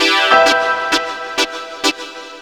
SYNTHLOOP3-R.wav